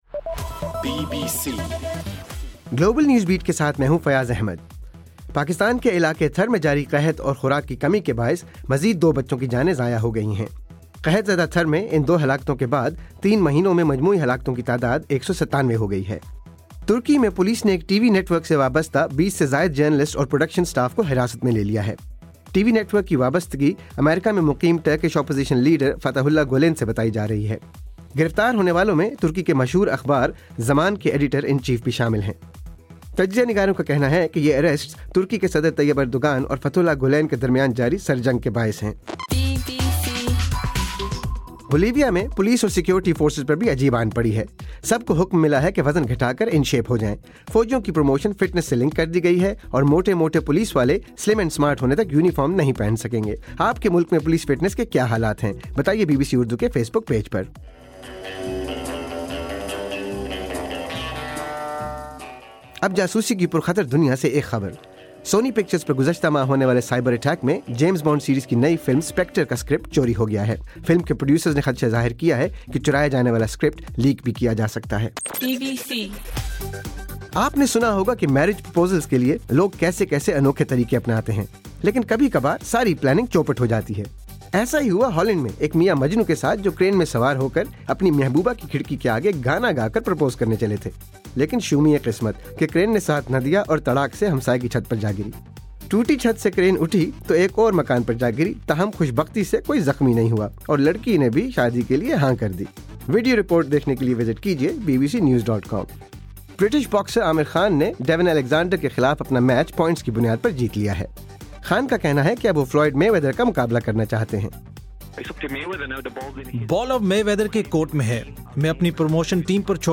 دسمبر 14: رات 10 بجے کا گلوبل نیوز بیٹ بُلیٹن